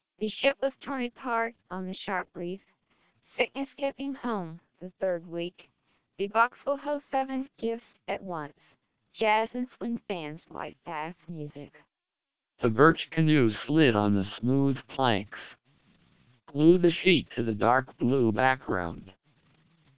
Also, all independent listeners preferred SPR, noting its significantly higher speech quality and intelligibility.